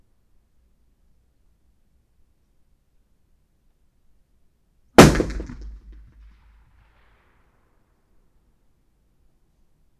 Environmental
Streetsounds
Noisepollution
UrbanSoundsNew / 01_gunshot /shot556_64_ch01_180718_162650_54_.wav